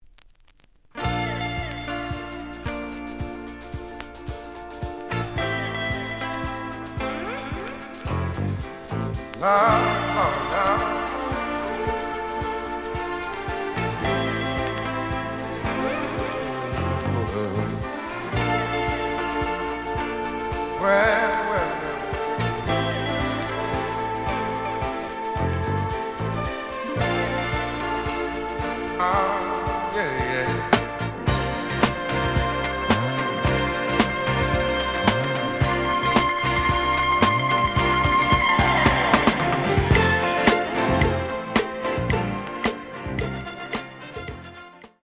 試聴は実際のレコードから録音しています。
●Genre: Soul, 70's Soul